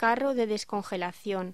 Locución: Carro de descongelación
voz